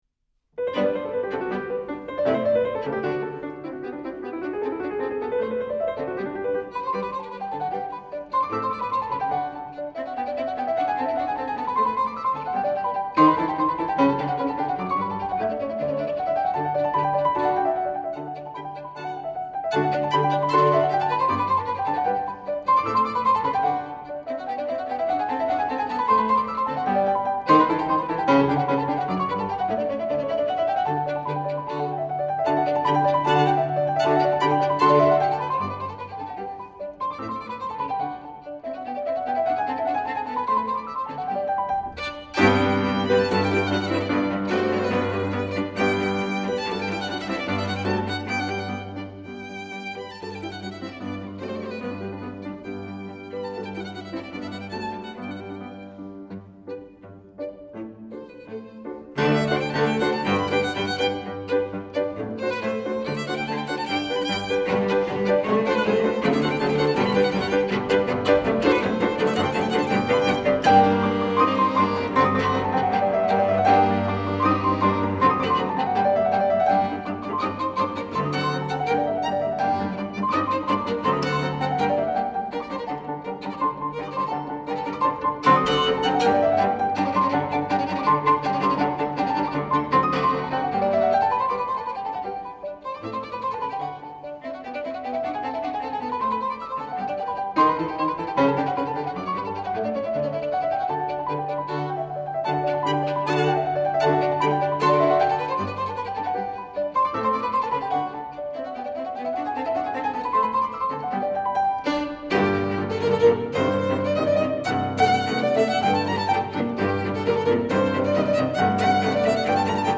haydn_trio24.mp3